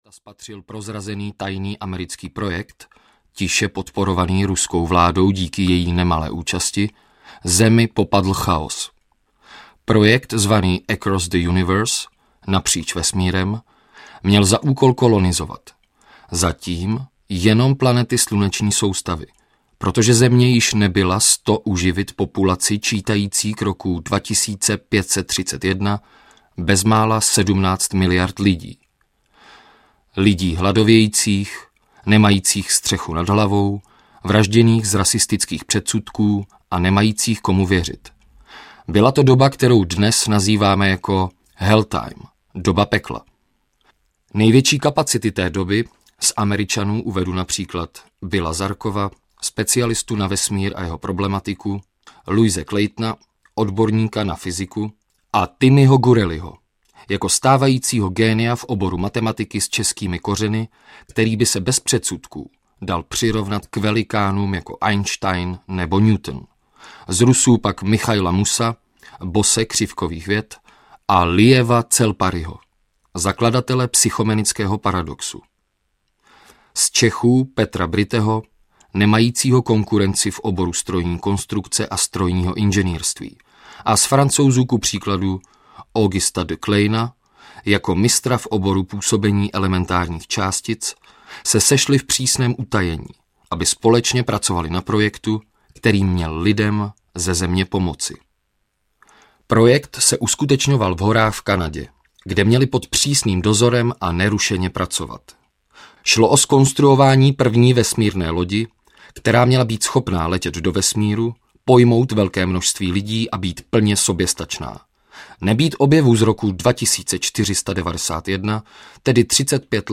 Entopolagurace audiokniha
Ukázka z knihy